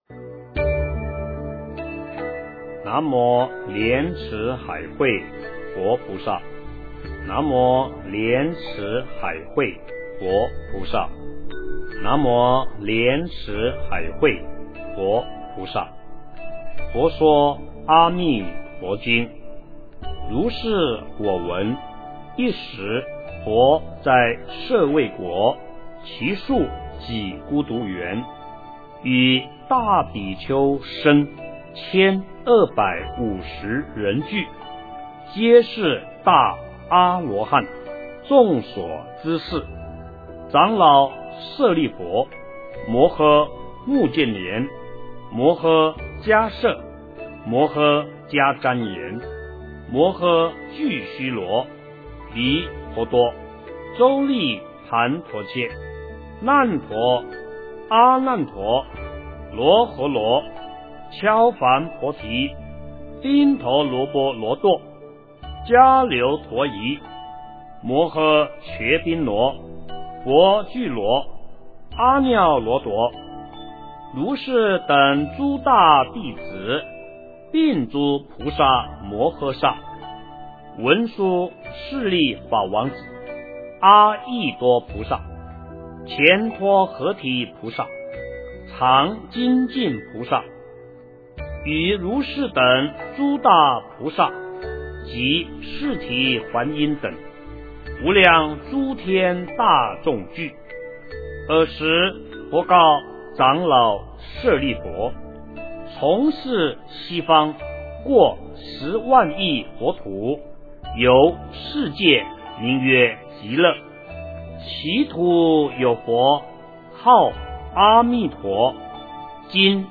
诵经
佛音 诵经 佛教音乐 返回列表 上一篇： 阿弥陀佛一字心咒 下一篇： 报父母恩咒 相关文章 《妙法莲华经》授记品第六 《妙法莲华经》授记品第六--佚名...